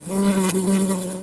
fly_buzz_flying_06.wav